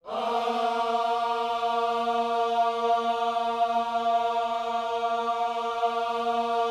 OHS A#3E.wav